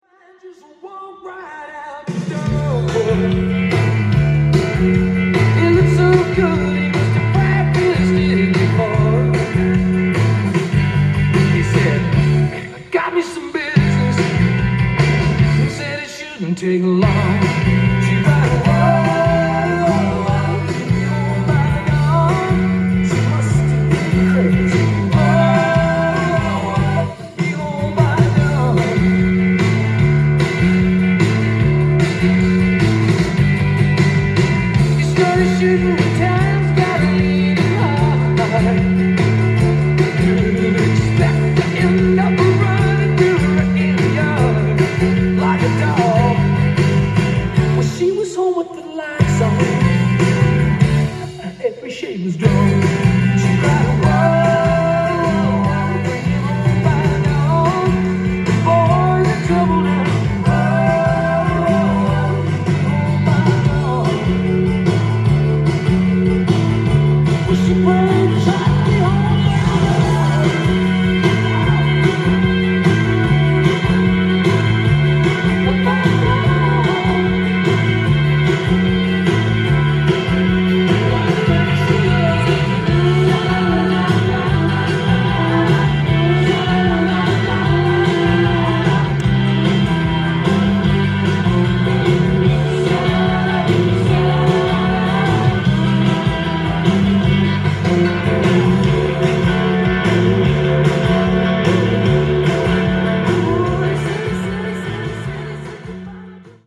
ジャンル：AOR
店頭で録音した音源の為、多少の外部音や音質の悪さはございますが、サンプルとしてご視聴ください。
美しいメロウなナンバーが素敵です！！